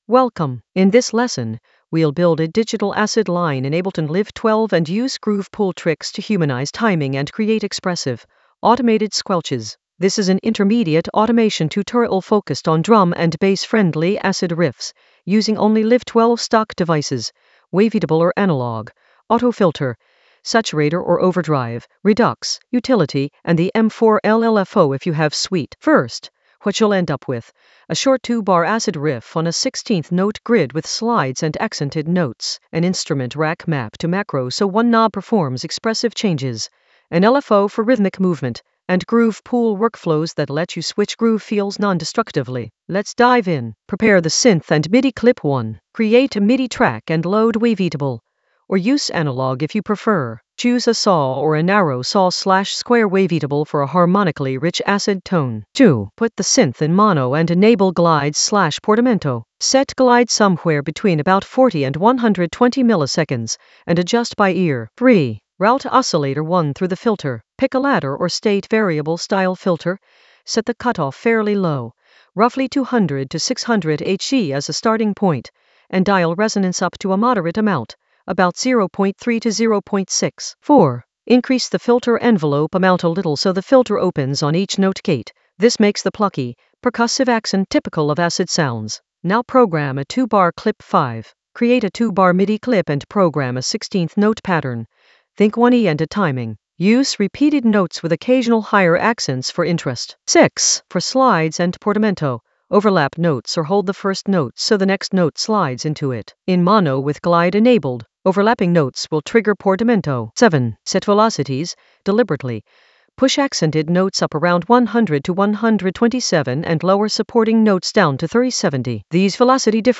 An AI-generated intermediate Ableton lesson focused on Digital acid line in Ableton Live 12 with groove pool tricks in the Automation area of drum and bass production.
Narrated lesson audio
The voice track includes the tutorial plus extra teacher commentary.